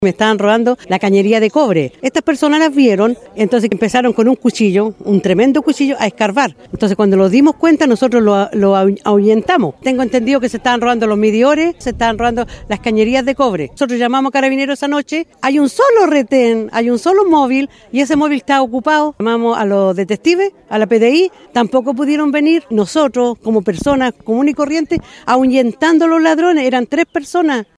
De hecho, una de las vecinas, quien pidió resguardo de su identidad por temor a represalias, comentó que hasta las cañerías de cobre son un “objeto valioso” para los ladrones. Acusa que aunque llamen a carabineros, estos no llegan cuando necesitan ayuda.